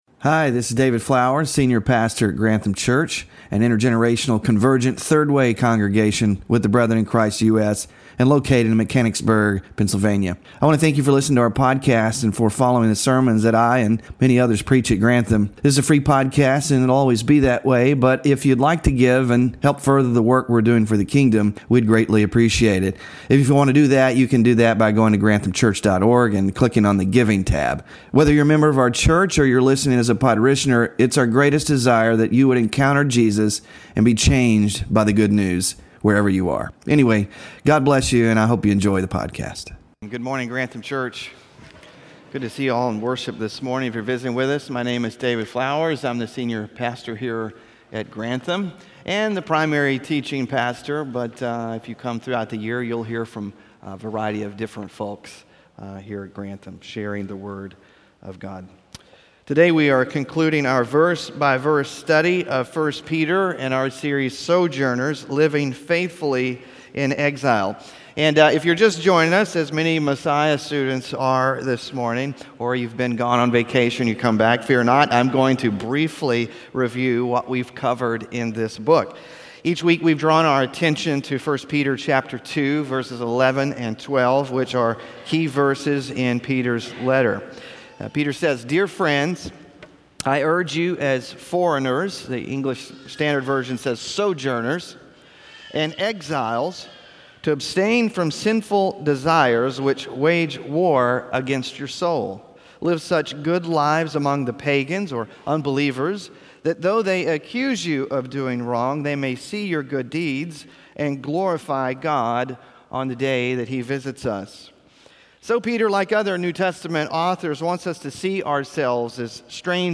SOJOURNERS SERIES Scripture Reading: 1 Peter 5:1–14; Mark 10:42-45; Philippians 2:3-8; James 4:6-10 Sermon Focus: Peter closes his letter by urging sojourners to live out the gospel in Christian community.